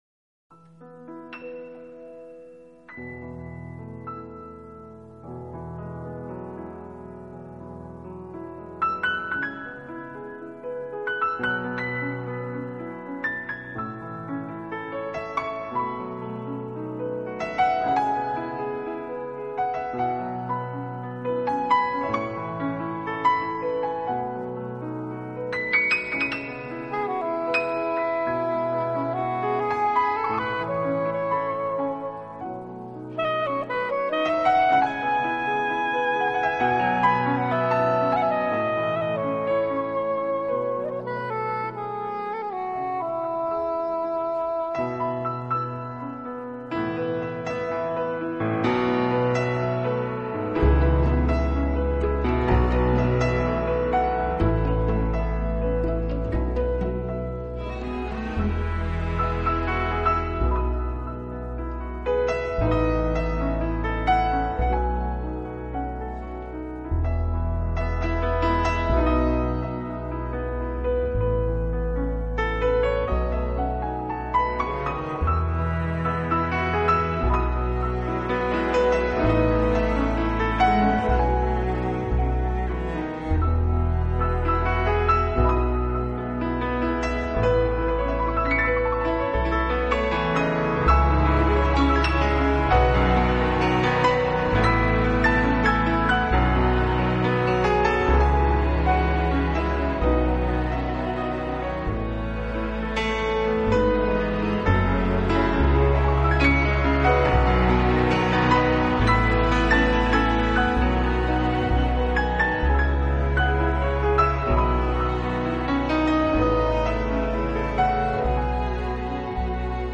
音乐类型：Instrumental 钢琴